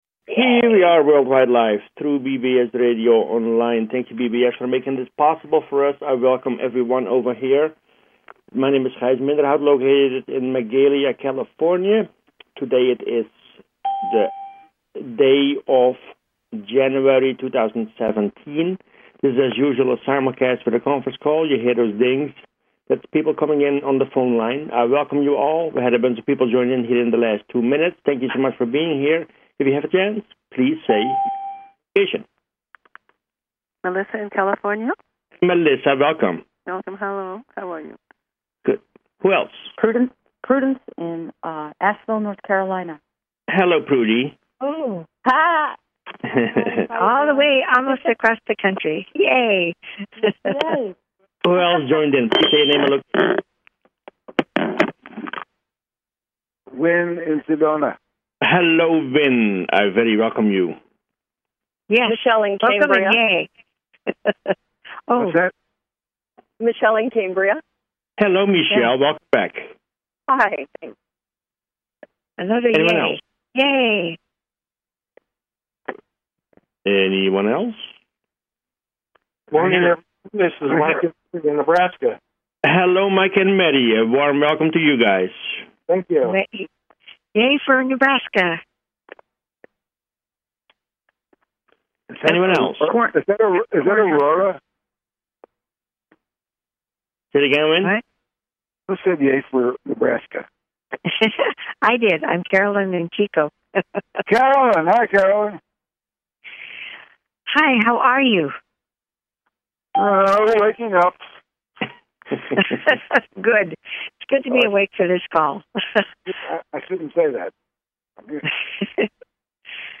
group meditation